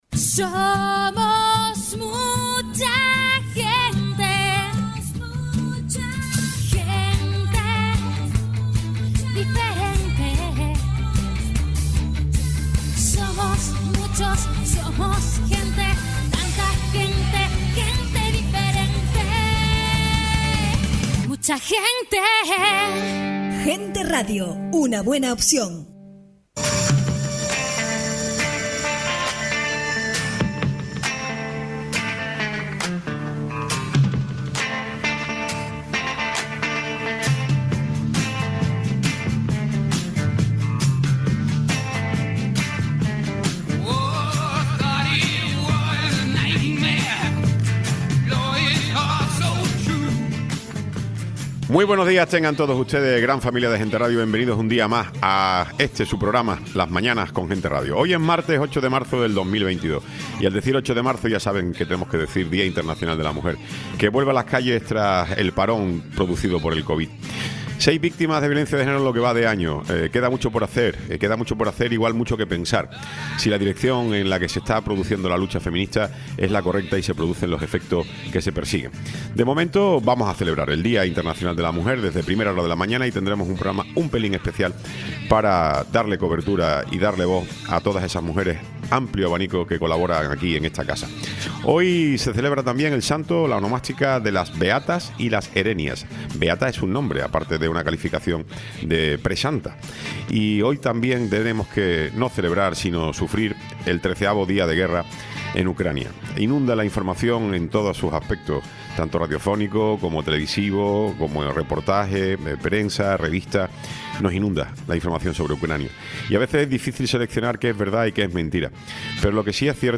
Espacio Día Internacional de la Mujer Tertulia